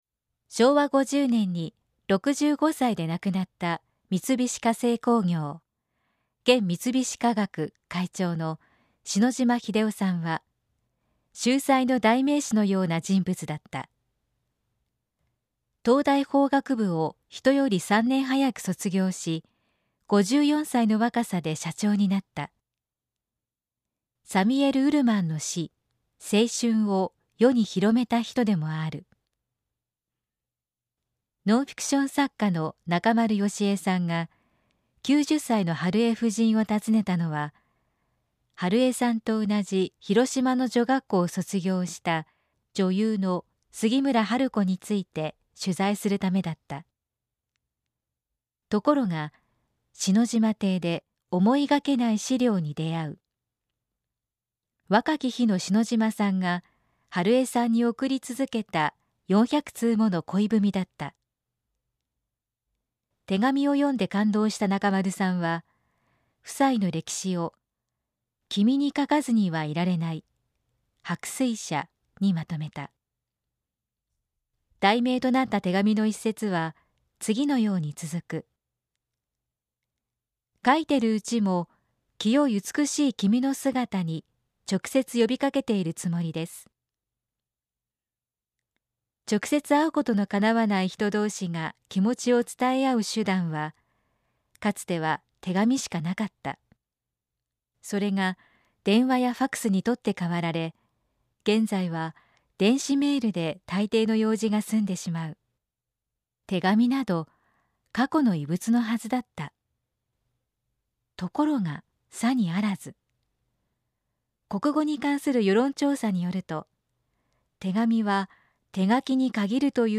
産経新聞1面のコラム「産経抄」を、局アナnetメンバーが毎日音読してお届けします。